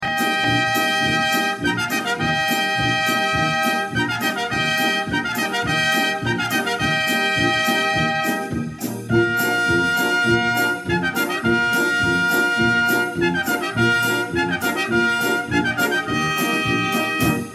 Trompette Mib (de Cavalerie)
Trompette.mp3